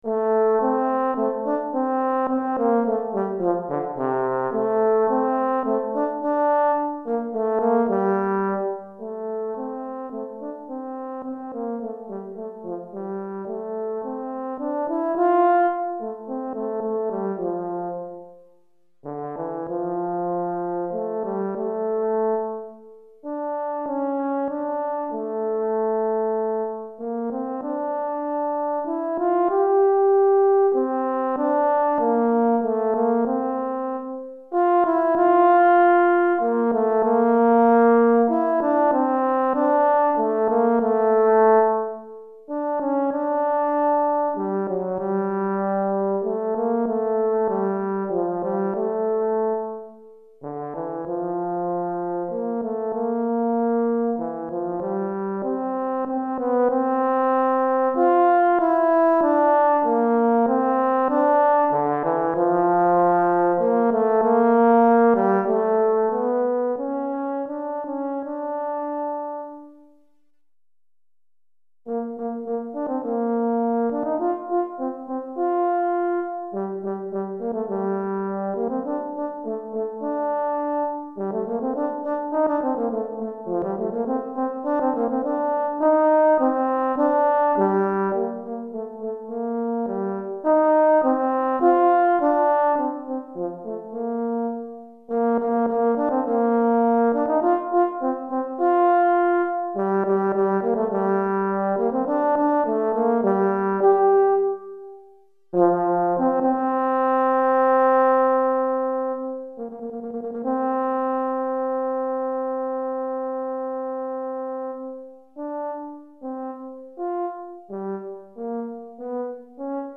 Pour cor solo